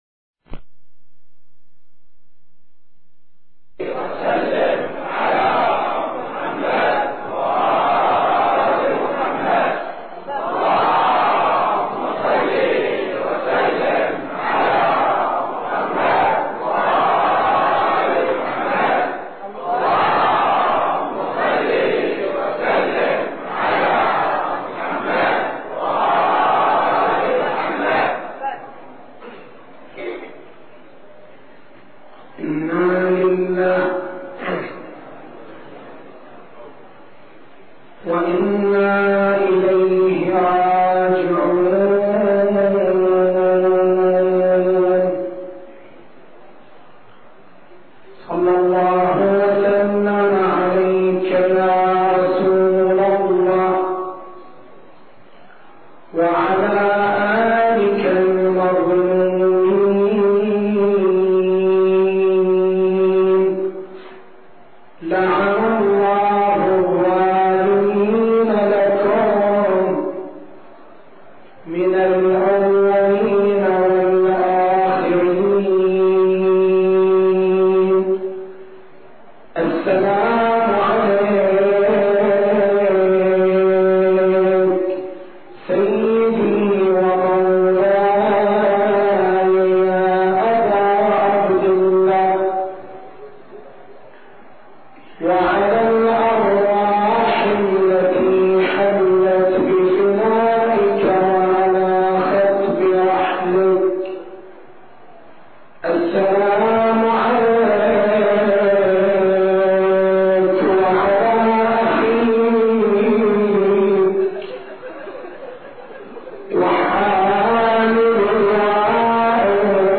تاريخ المحاضرة: 07/01/1425 نقاط البحث: رؤية الإسلام للحداثة رؤية الإسلام للفن التسجيل الصوتي: تحميل التسجيل الصوتي: شبكة الضياء > مكتبة المحاضرات > محرم الحرام > محرم الحرام 1425